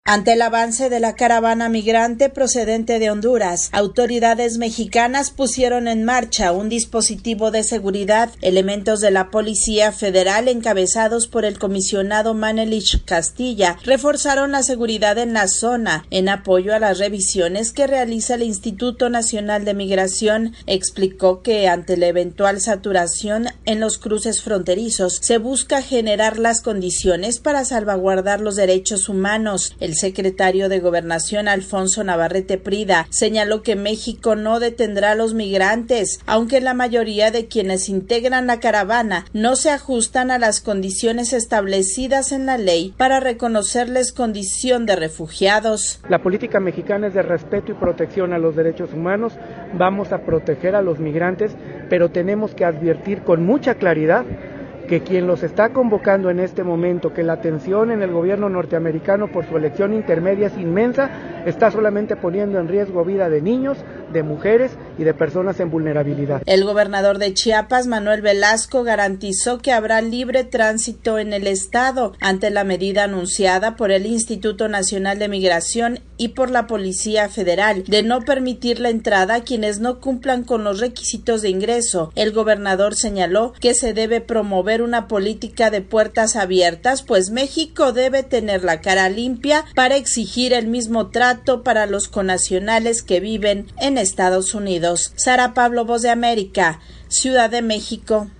VOA: Informe desde Mexico